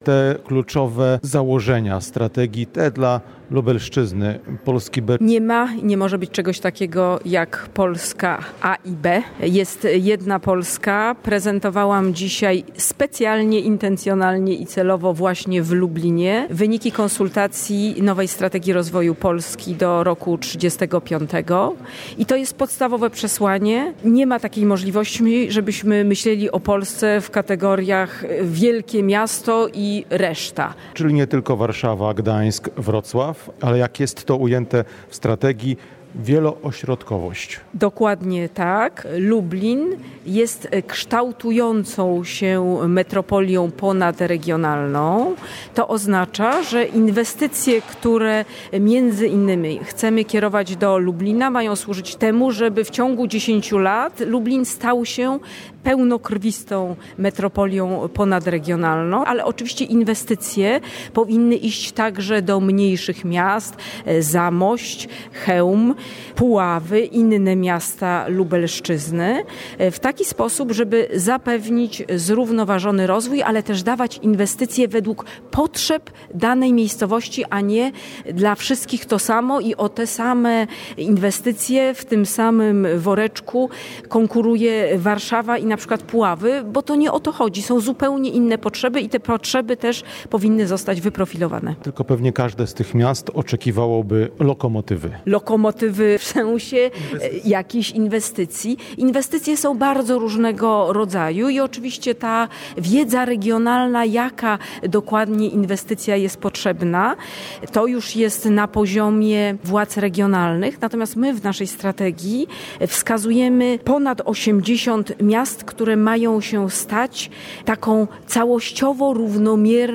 Z minister Katarzyną Pełczyńską-Nałęcz rozmawia